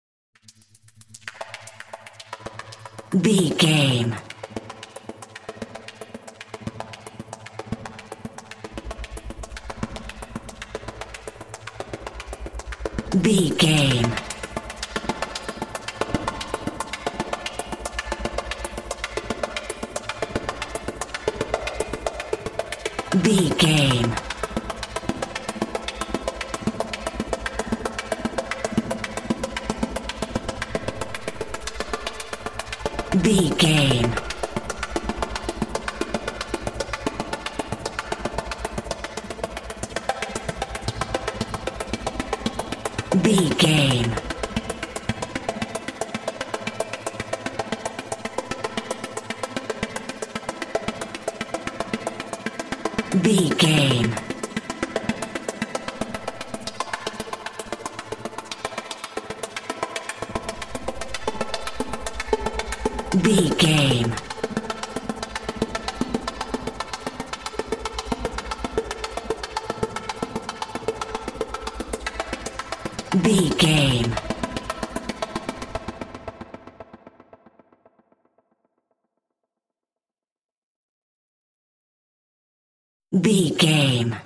Atonal
tension
ominous
dark
haunting
eerie
Horror synth
Horror Ambience
synthesizer